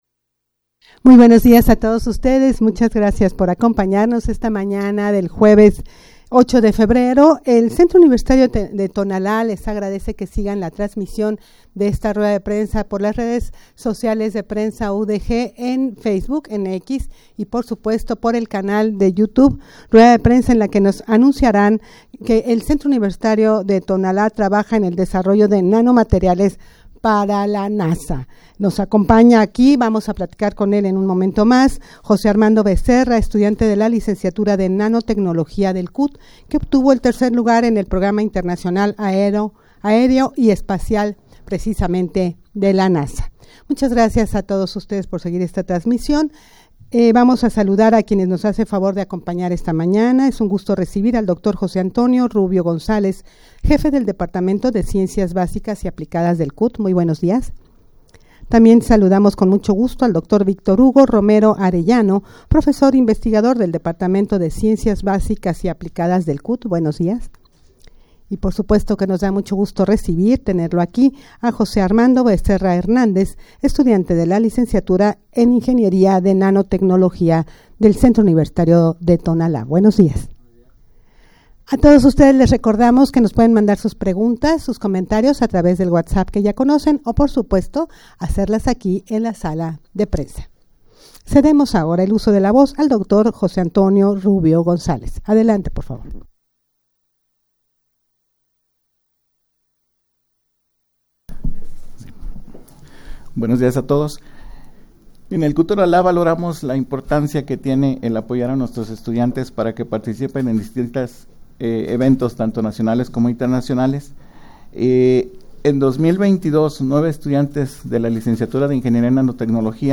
Audio de la Rueda de Prensa
rueda-de-prensa-para-anunciar-que-en-el-cut-trabajan-en-el-desarrollo-de-nanomateriales-para-la-nasa.mp3